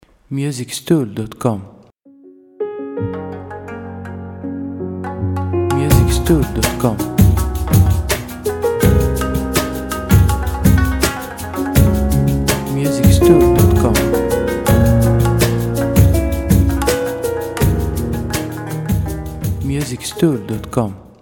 • Type : Instrumental
• Bpm : Moderato
• Genre : Romantic Soundtrack / New Age